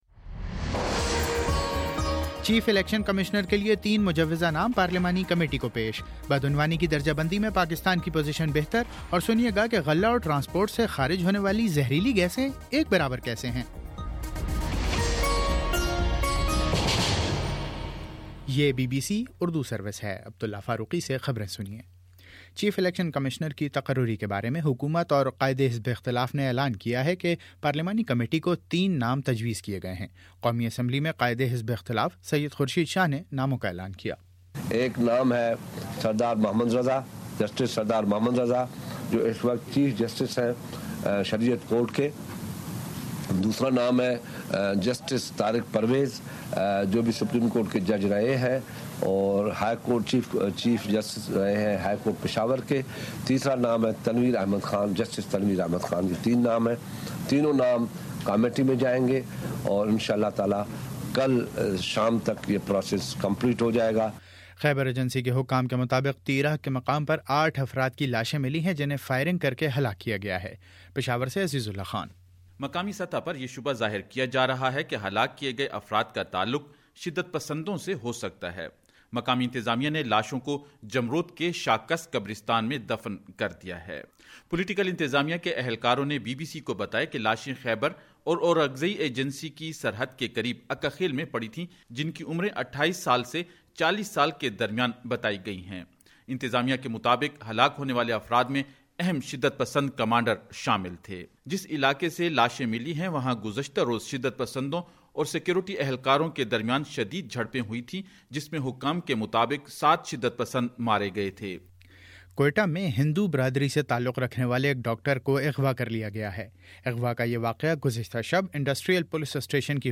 دسمبر03 : شام چھ بجے کا نیوز بُلیٹن